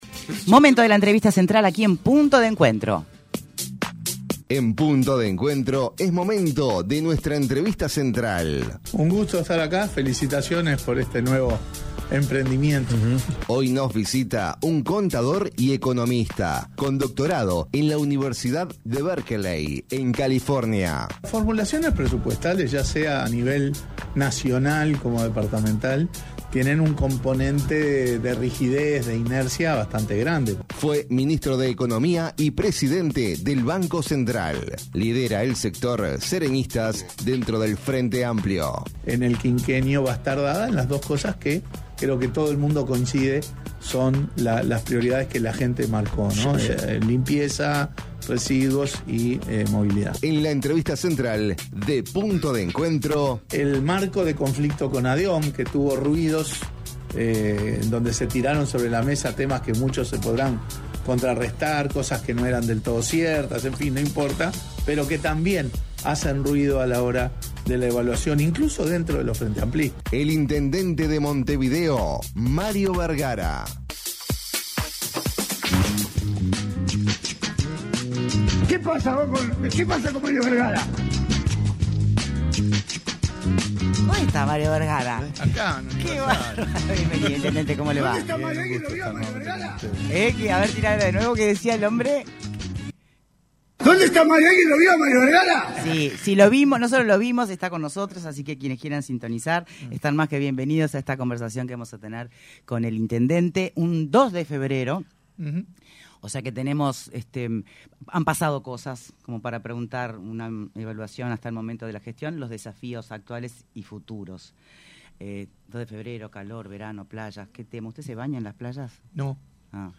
El intendente de Montevideo, Mario Bergara dijo en entrevista con Punto de Encuentro que hay varias posibilidades de financiamiento para las cuestiones presupuestales de la comuna.